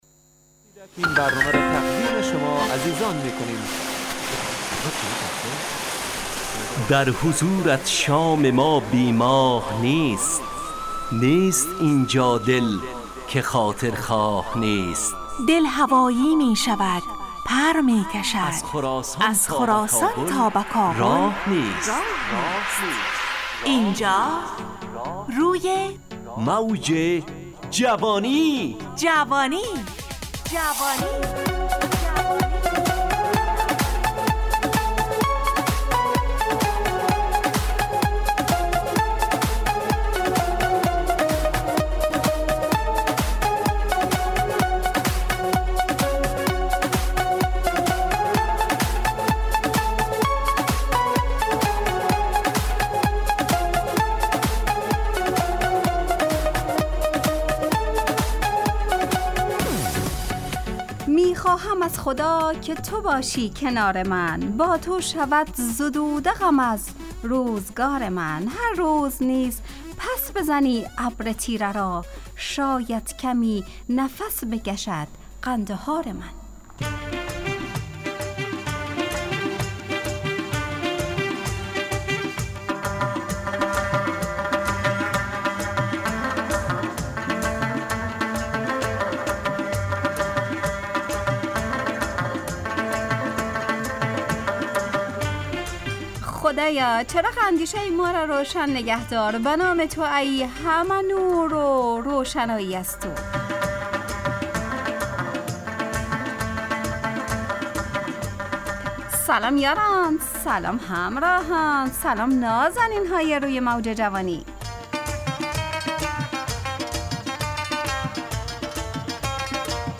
روی موج جوانی، برنامه شادو عصرانه رادیودری.
همراه با ترانه و موسیقی .